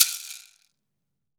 WOOD SHAKER4.WAV